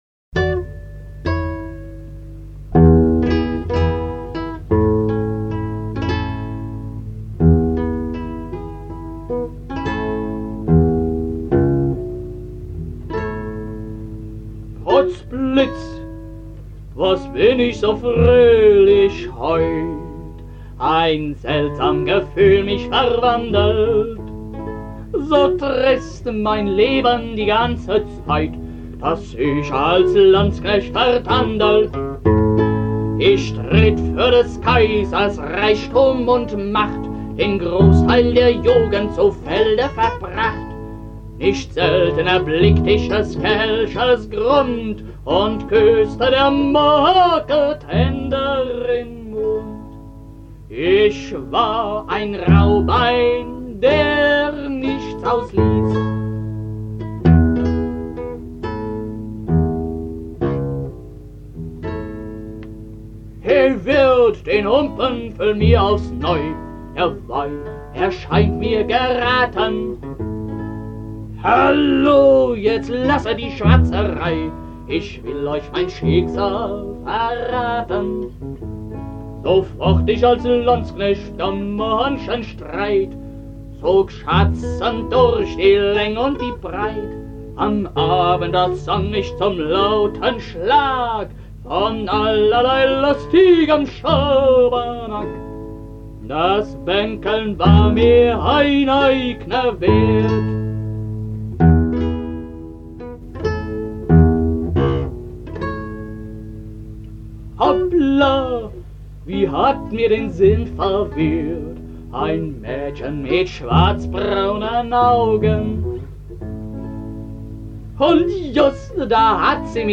(Balladenfassung)